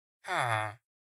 minecraft_villager_huh.mp3